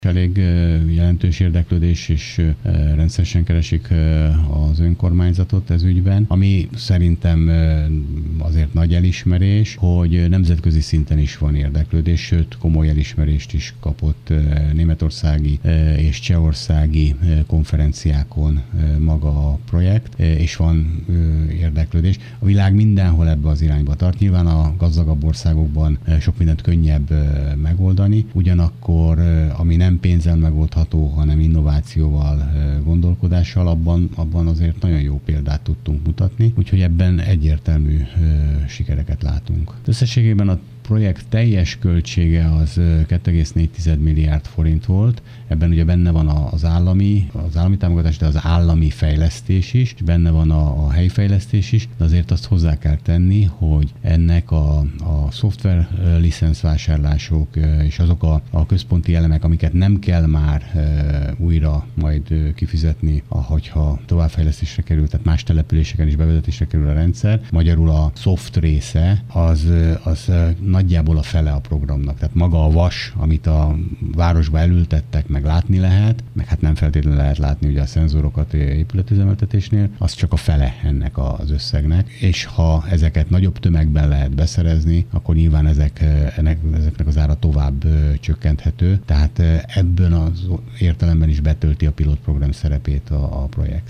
Pápai Mihály polgármestert hallják.